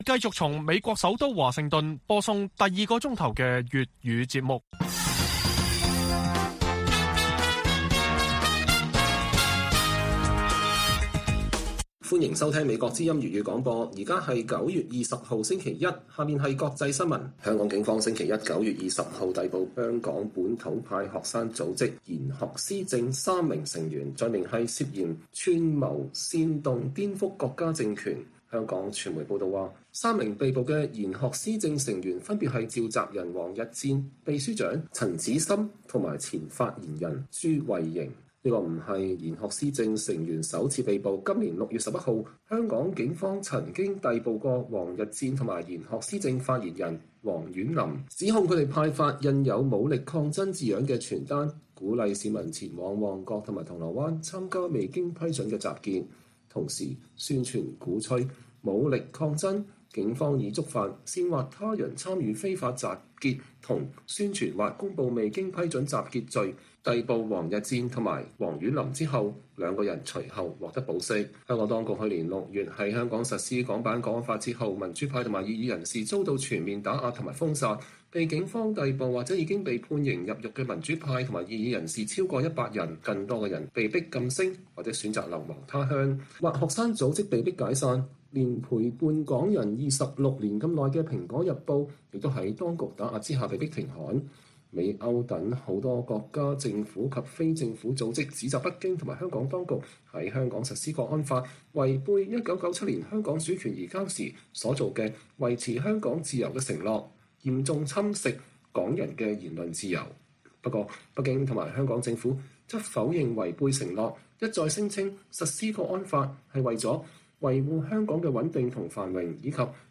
粵語新聞 晚上10-11點: 從五眼到三邊，美國重塑抗中盟友圈？